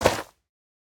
Minecraft Version Minecraft Version latest Latest Release | Latest Snapshot latest / assets / minecraft / sounds / block / soul_soil / step2.ogg Compare With Compare With Latest Release | Latest Snapshot
step2.ogg